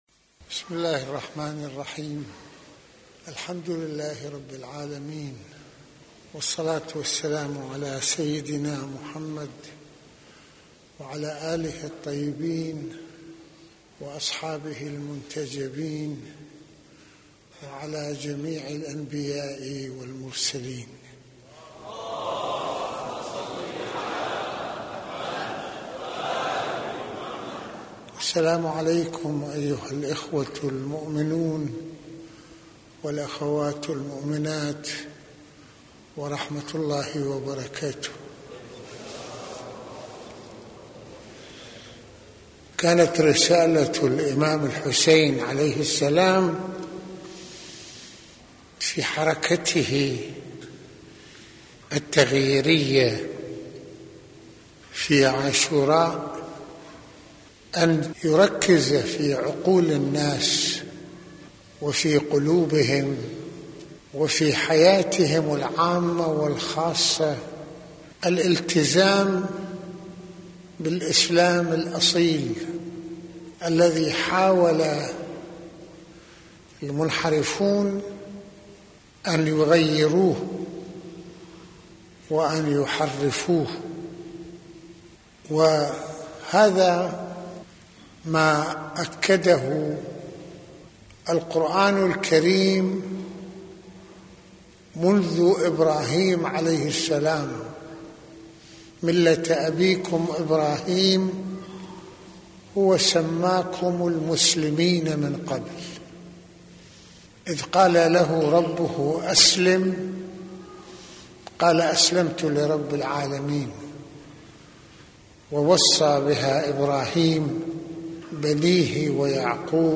- يتحدث سماحة المرجع السيد محمد حسين فضل الله (رض) في هذه المحاضرة عن قيمة عاشوراء كتأصيل لروحية الإسلام في مواجهة المنحرفين ، ويتناول معنى الإسلام في اختصاره لخط توحيد الله من خلال حركة الرسالات والأنبياء وما يجب على المسلمين أن ينفتحوا عليه في هذا المضمار وأن يتحلوا به من أخلاقيات على مستوى الحياة الخاصة والعامة...